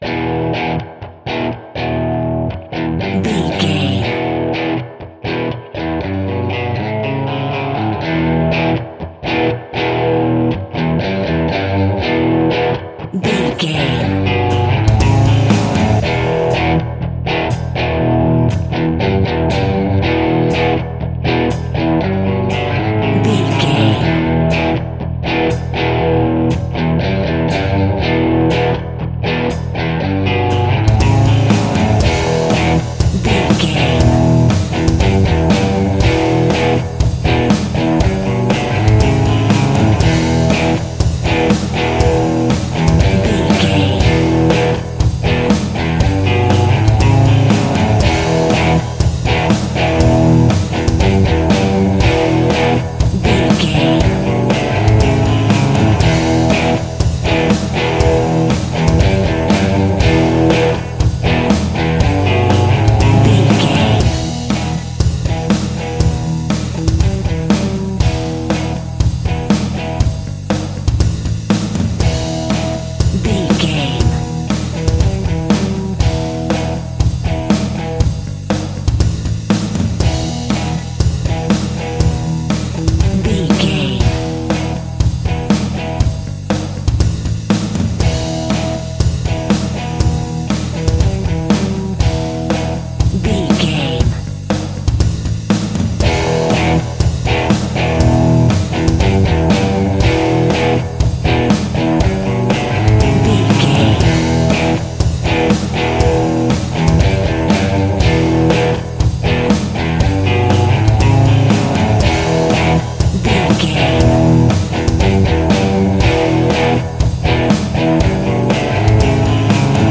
Epic / Action
Fast paced
Aeolian/Minor